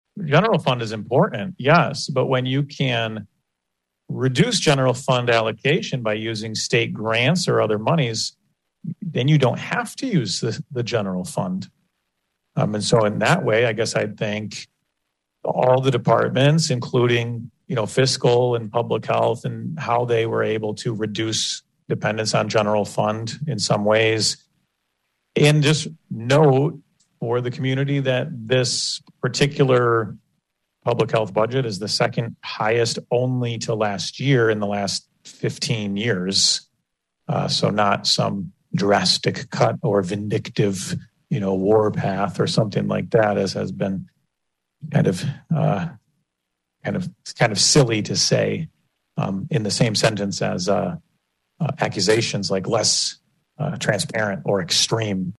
Board Chairman Joe Moss of Hudsonville justified the vote.